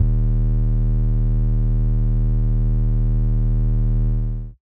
TGOD SubBass.wav